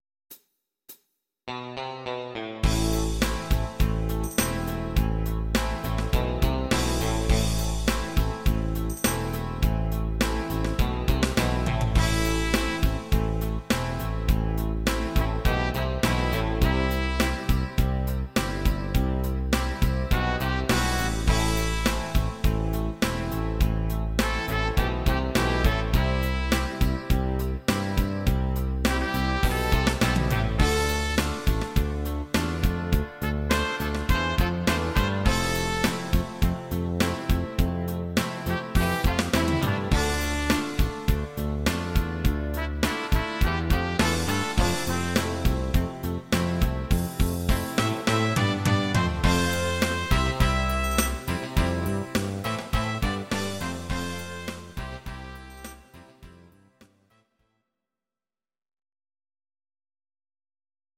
Audio Recordings based on Midi-files
Rock, 2000s